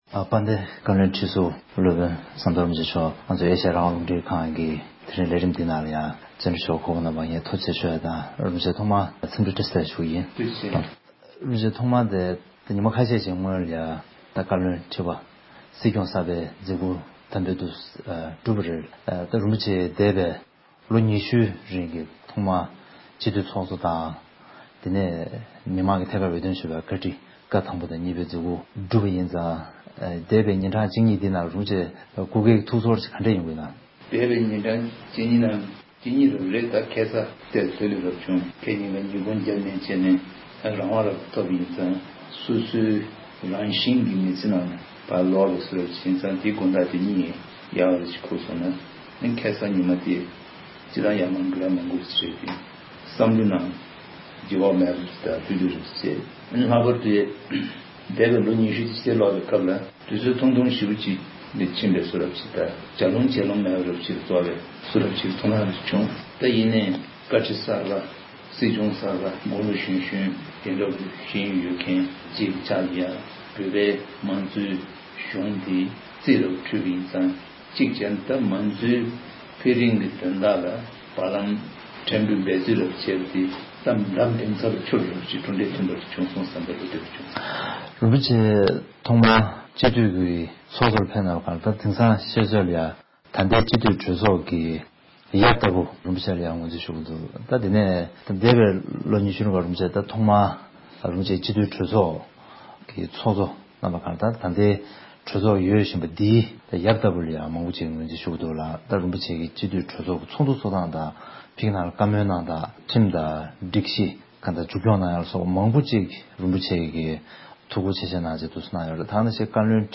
རིན་པོ་ཆེ་མཆོག་ལ་བཅར་འདྲི།